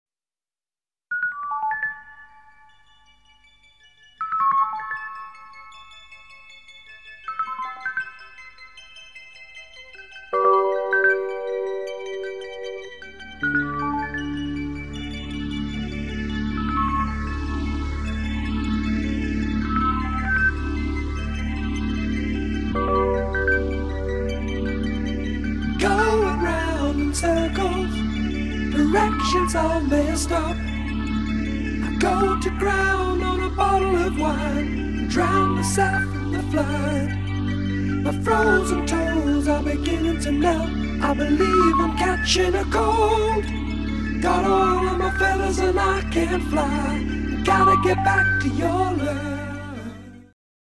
• Качество: 128, Stereo
спокойные